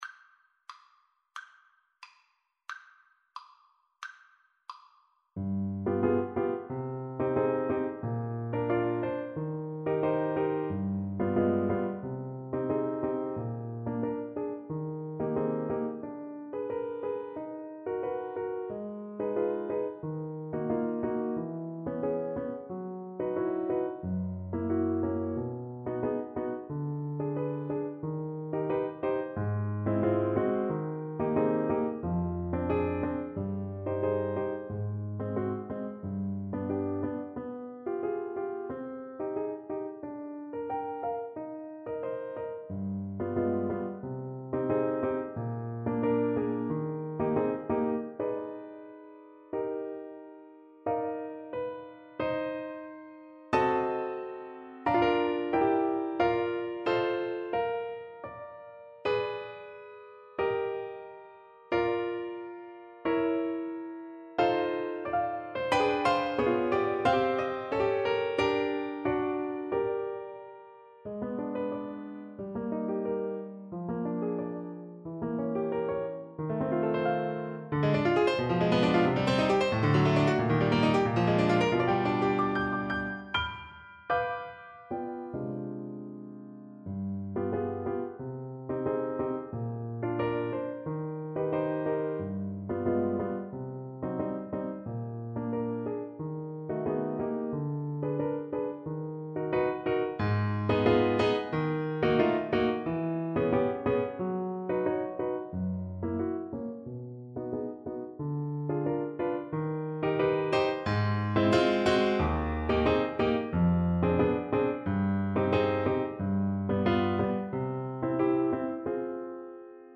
2/4 (View more 2/4 Music)
Andante = 90 Andante
Classical (View more Classical Trombone Music)